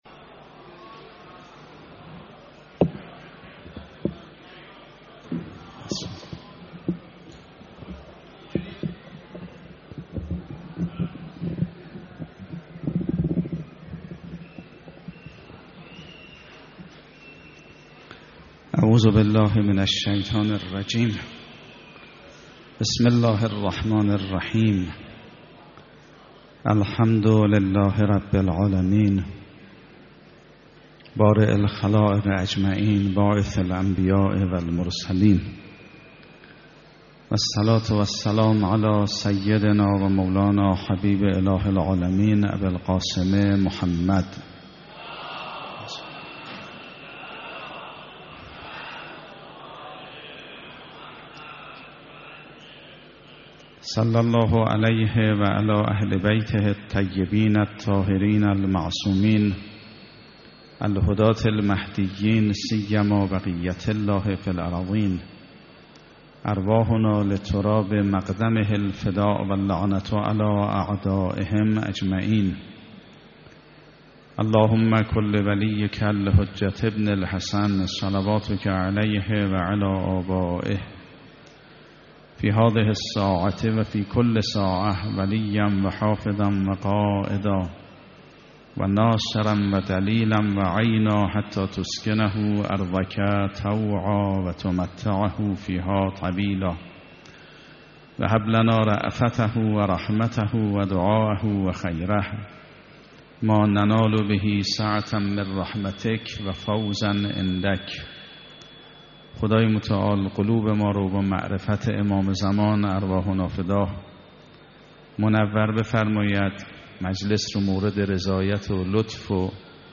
30 بهمن 96 - حرم حضرت معصومه - شرح زیارت حضرت زهرا علیهاالسلام
سخنرانی